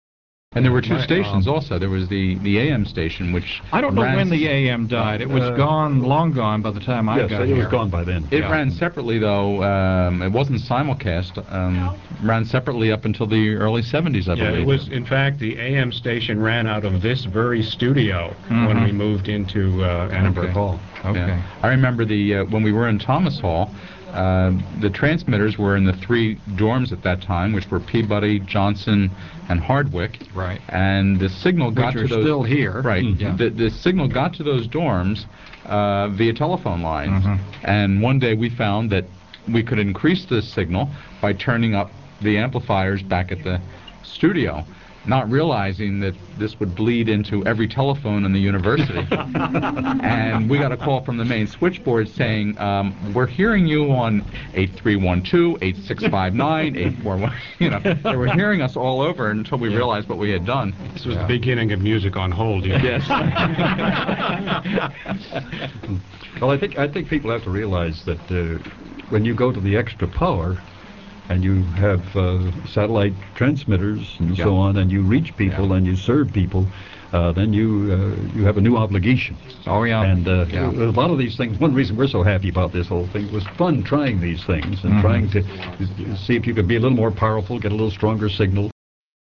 Here are excerpts from that broadcast: